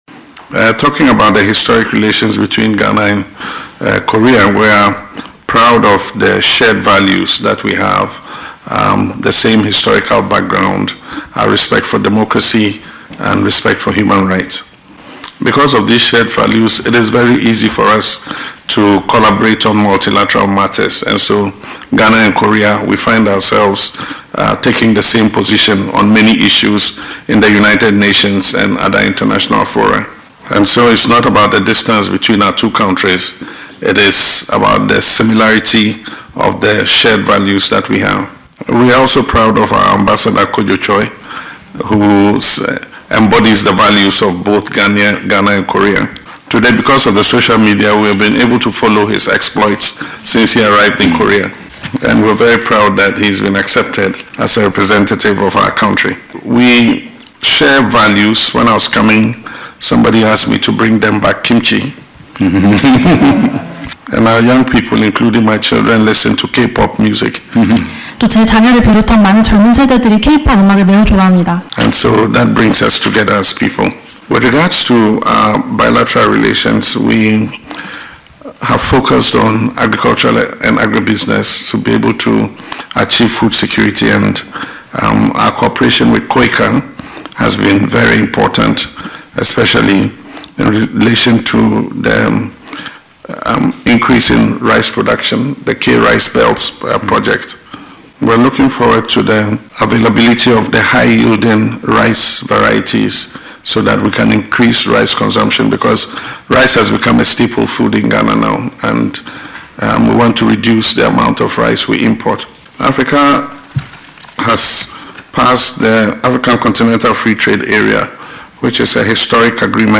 LISTEN TO PRESIDENT MAHAMA IN THE AUDIO BELOW: